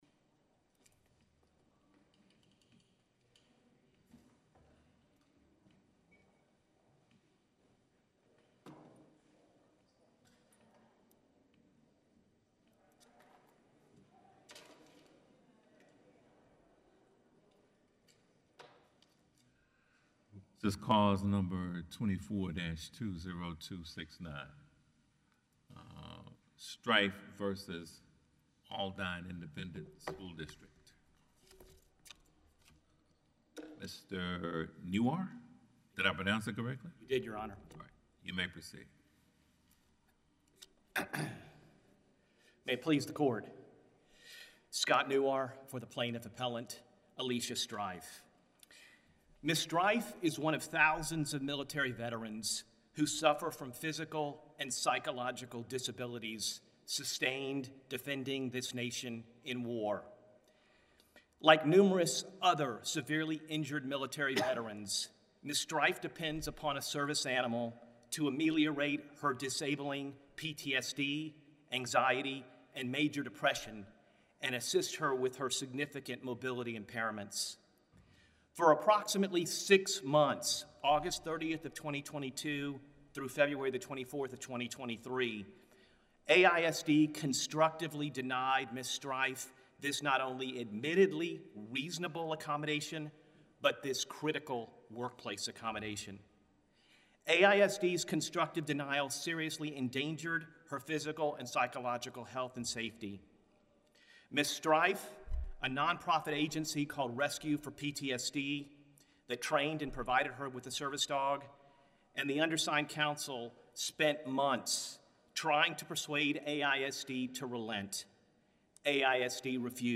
In the Fifth Circuit